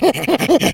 chimp.wav